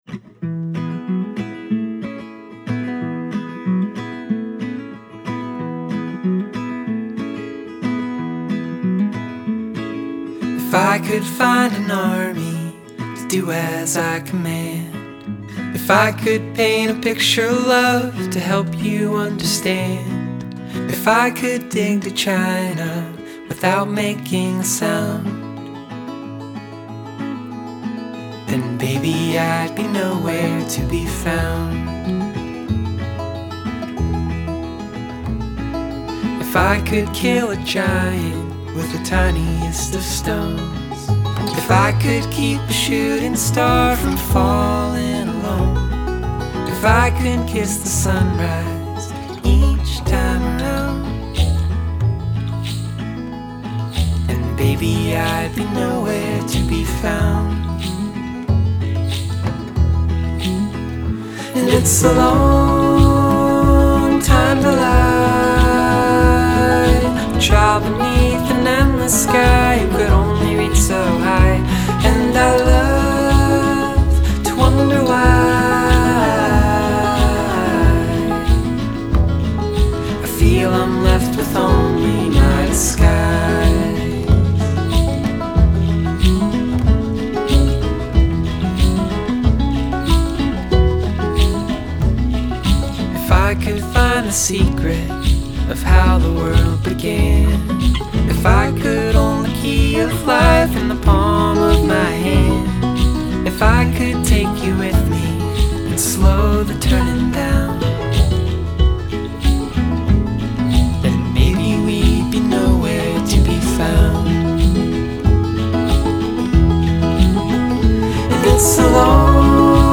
A lovely and sunny acoustic folk song